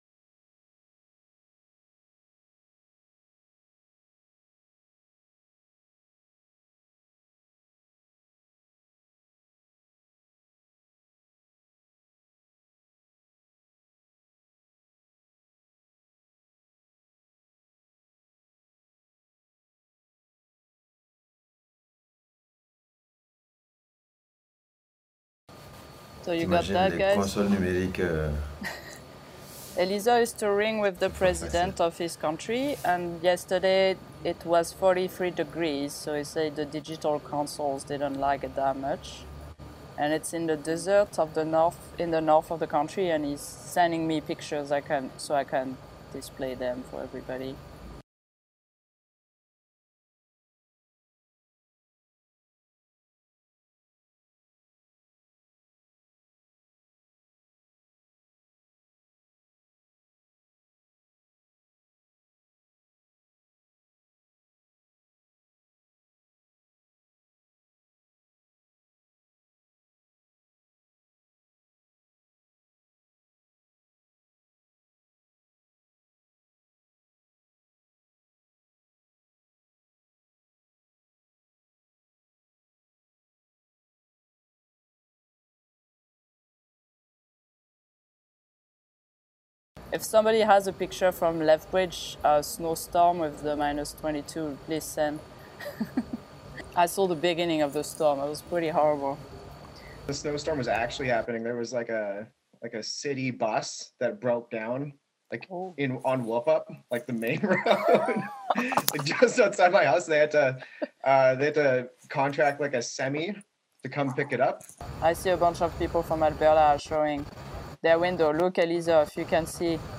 Roundtable discussion about De-colonizing the Digital Audio Workstation | Canal U